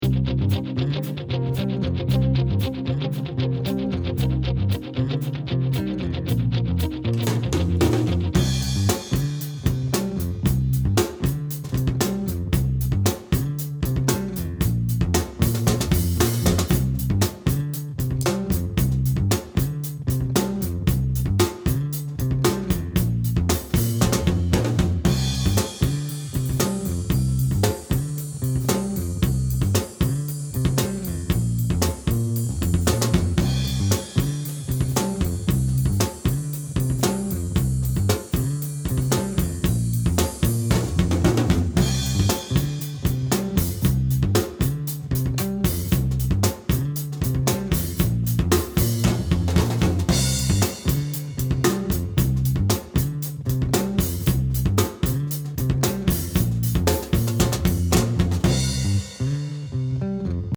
First thing I've done is to get the room treated with a comforters on the wall.
Second is getting the drums to sound ok with the Apex 435 .
I'm only looking for balance, levels with bass...etc..